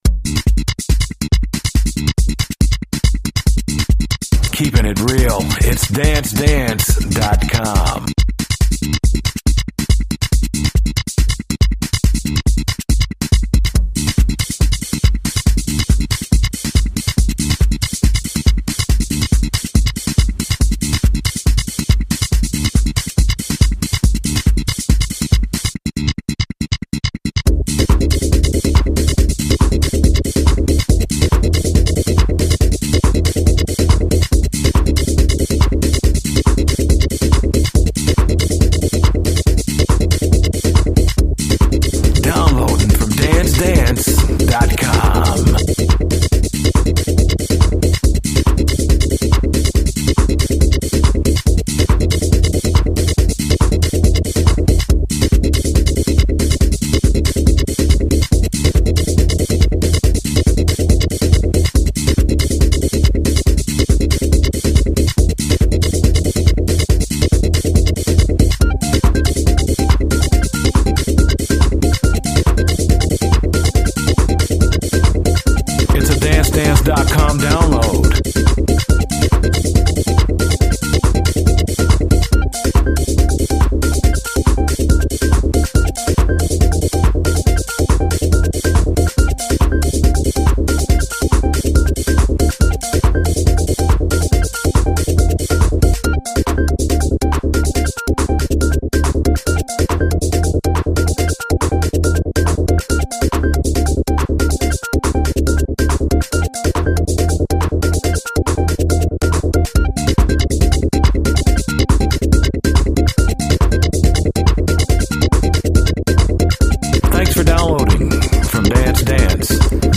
techno joint
Real Techno